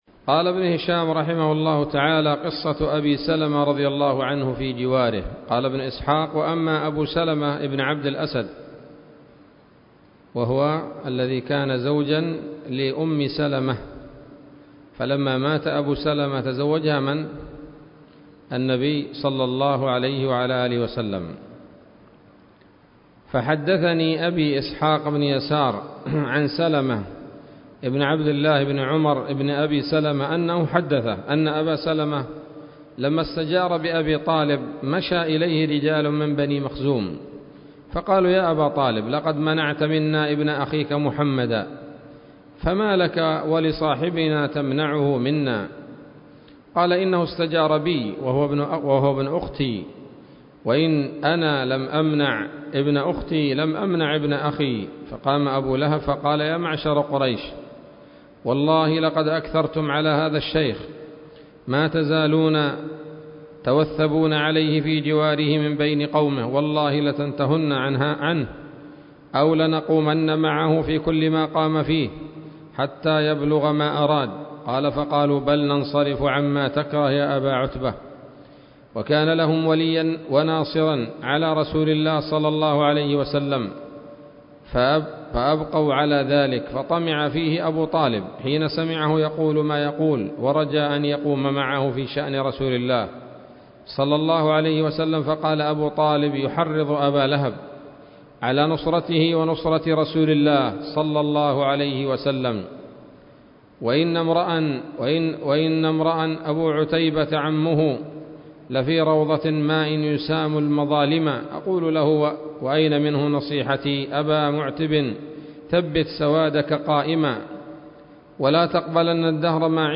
الدرس الحادي والأربعون من التعليق على كتاب السيرة النبوية لابن هشام